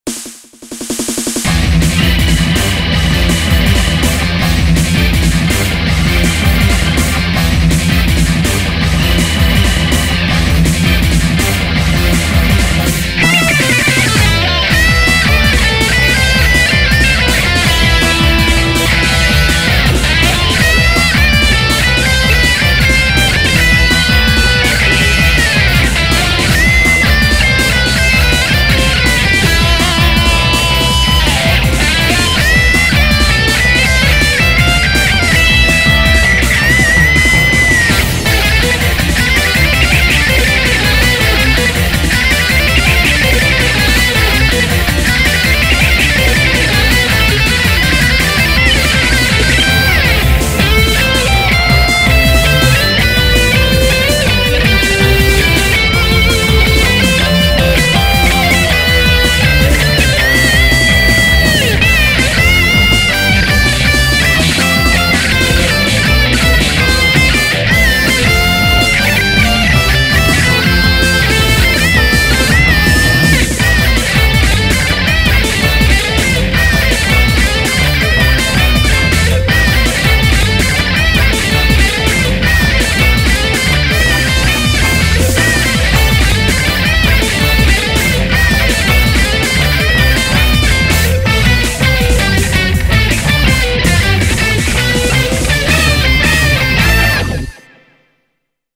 BPM163
Audio QualityPerfect (High Quality)
rock based music!